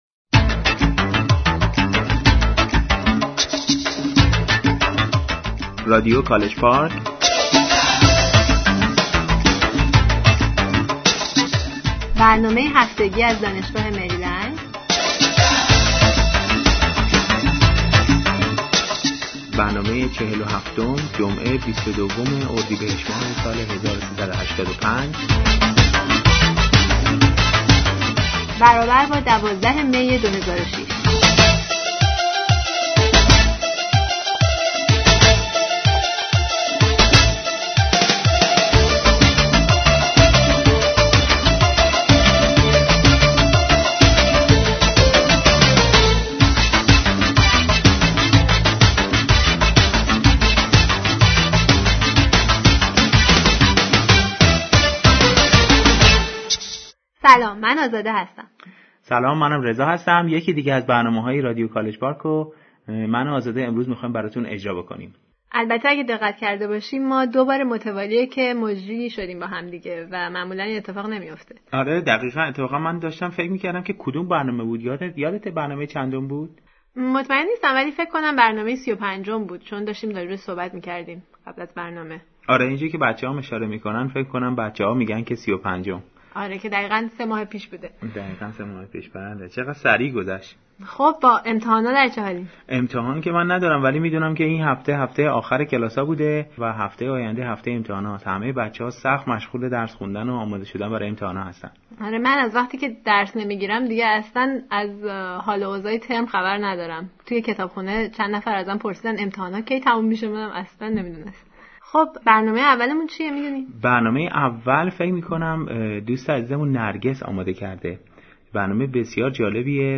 Is Farsi in Danger? Speech